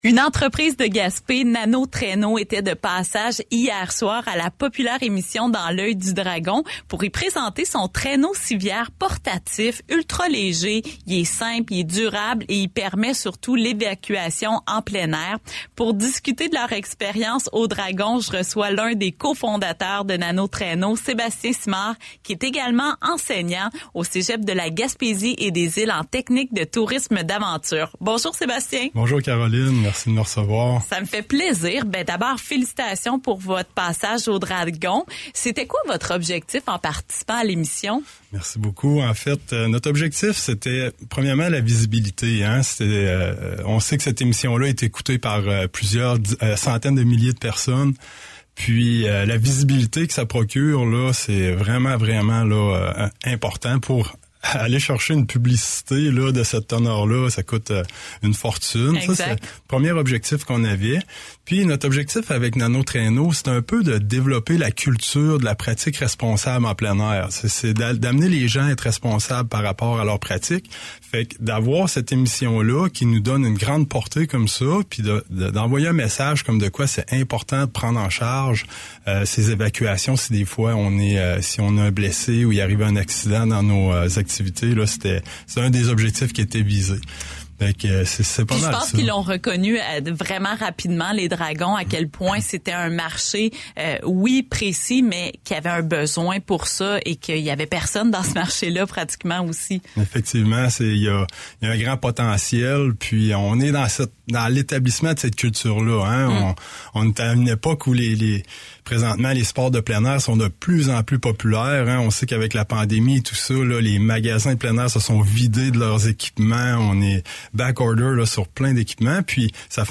a reçu en studio